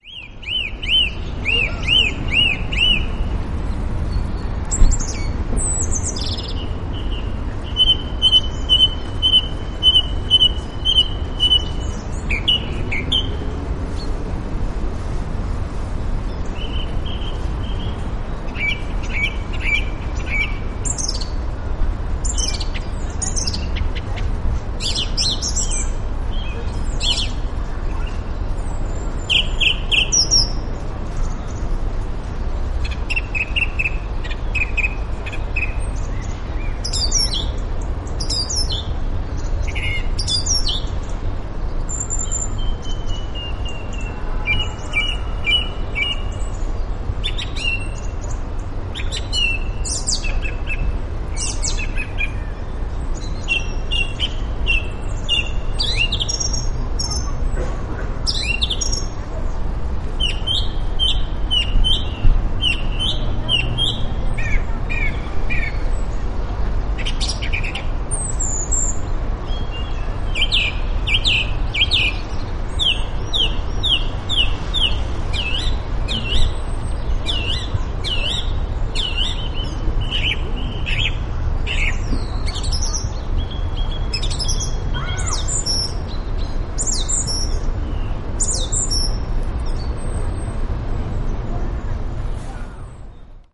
Grive musicienne (Turdus philomelos)
Deux grives musiciennes se répondent.